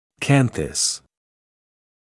[‘kænθəs][‘кэнсэс]угол глазной щели